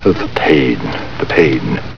Lost in Space TV Show Sound Bites